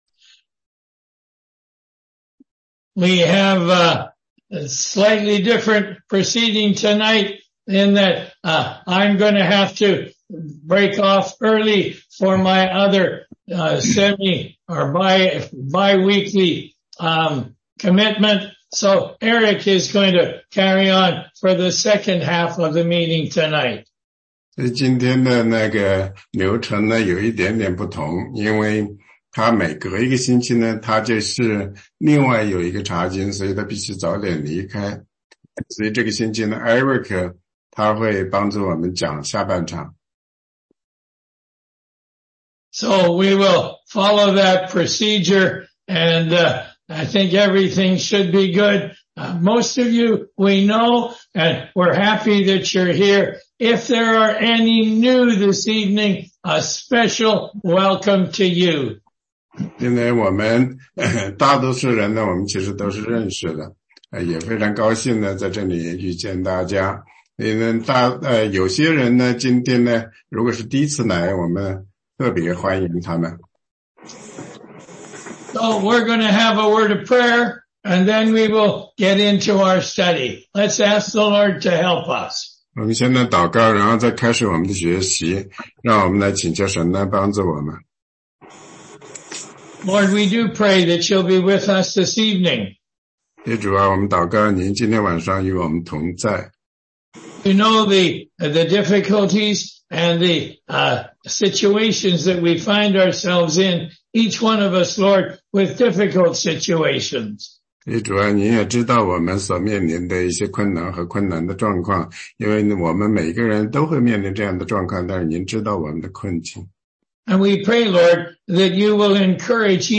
16街讲道录音 - 福音基础
福音课第七十七讲（中英文）.mp3